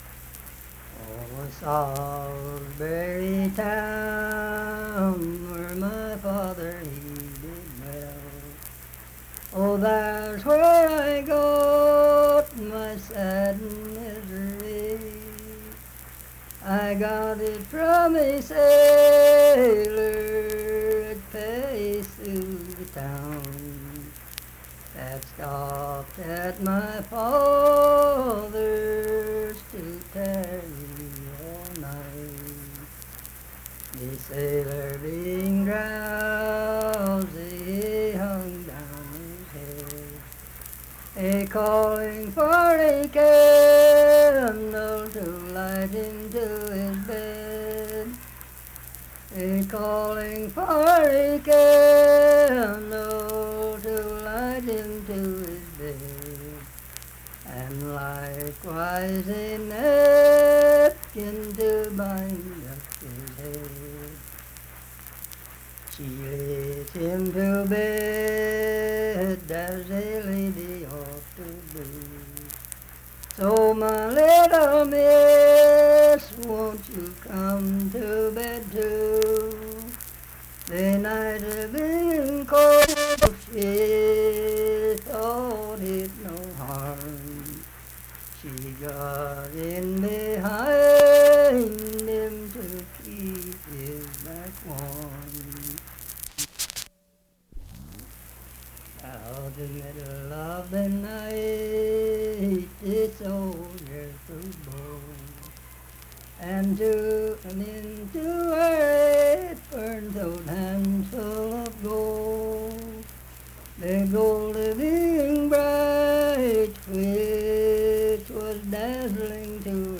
Unaccompanied vocal music
Verse-refrain, 8(6).
Voice (sung)
Harts (W. Va.), Lincoln County (W. Va.)